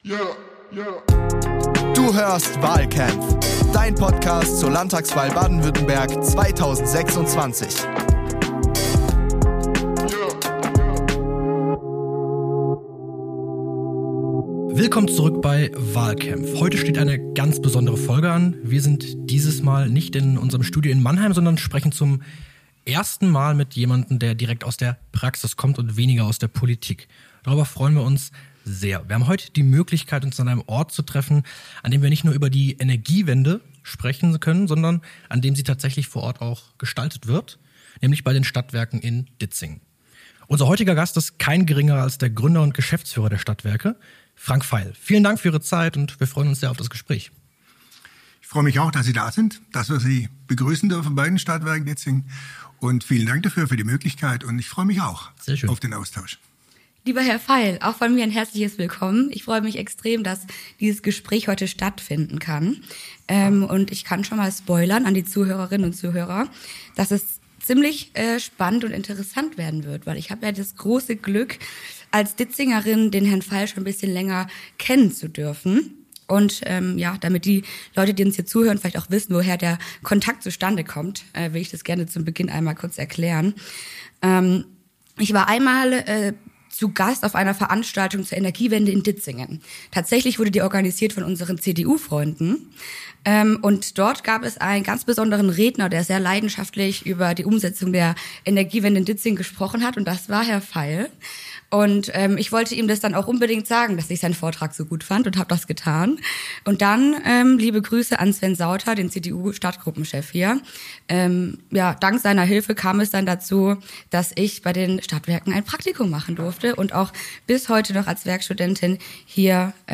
Heute verlassen wir unsere Komfortzone im Studio.